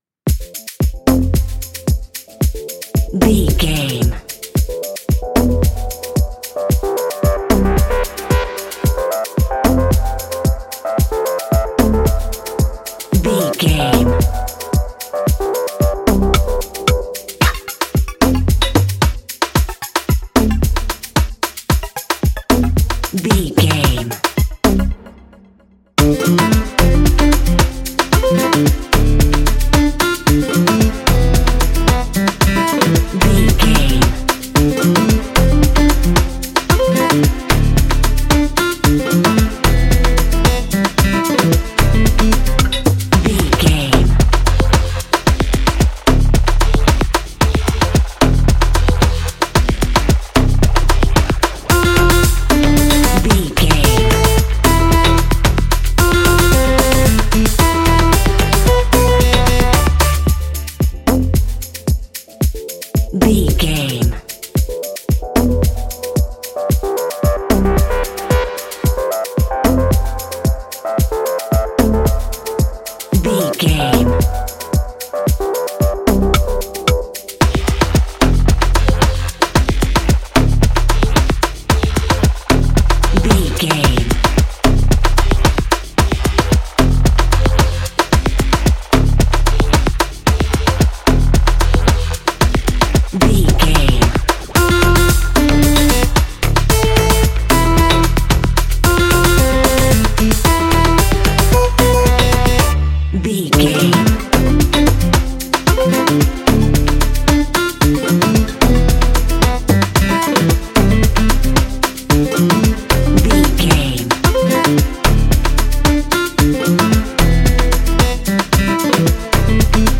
Ionian/Major
afrobeat
groovy
synthetic
warm
upbeat